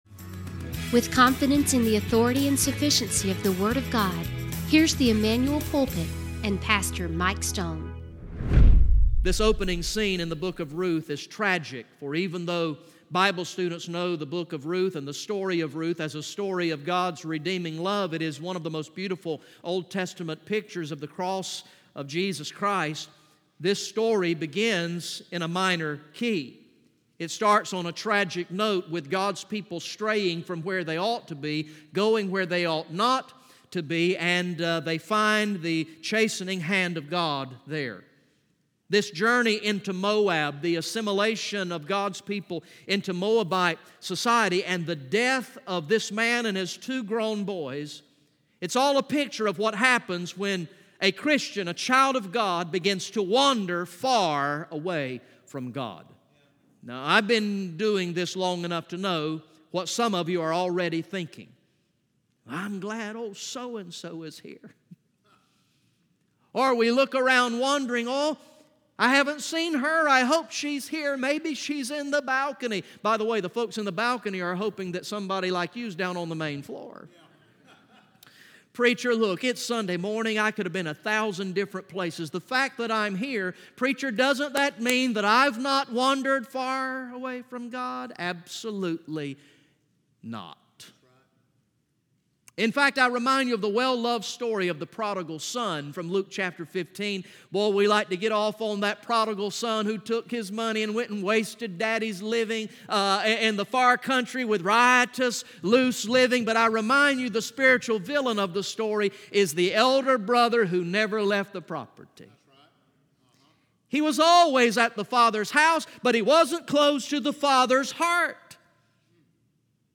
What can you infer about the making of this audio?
From the morning worship service on Sunday, August 11, 2019